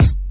Dre Kick2.wav